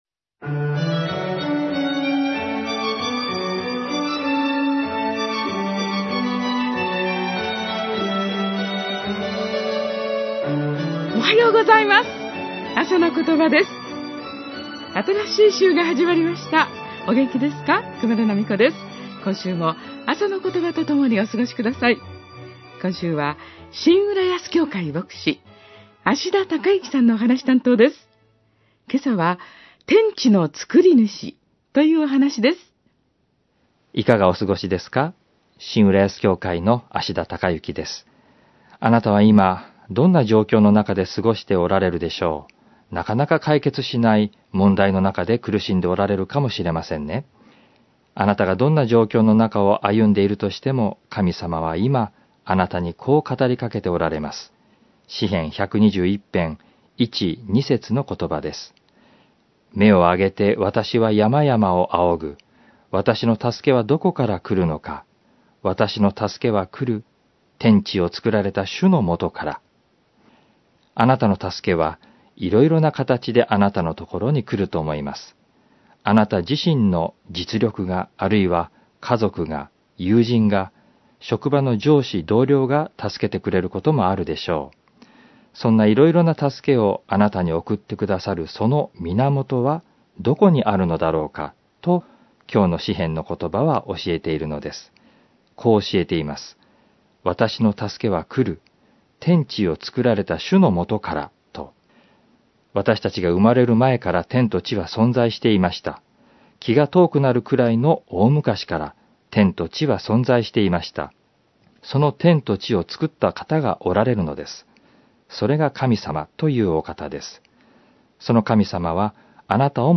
メッセージ： 　天地の造り主